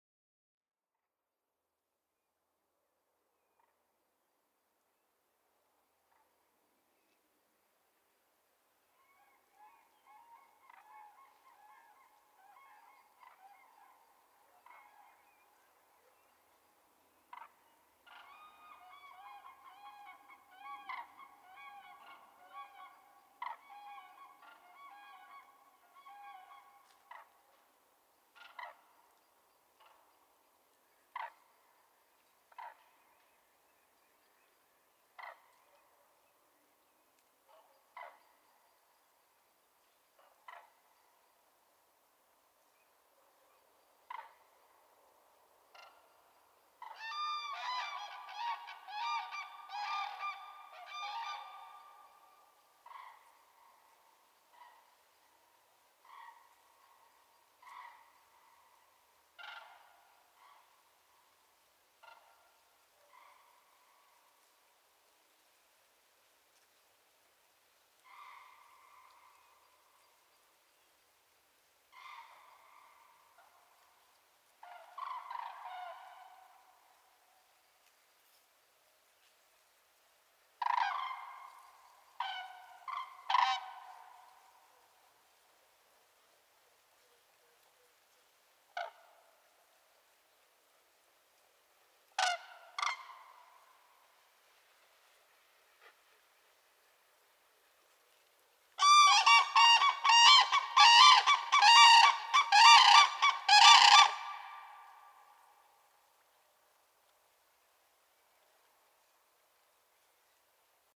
PFR09761, 140304, Common Crane Grus grus, territorial call, calls in flight
Wildenhainer Bruch, Germany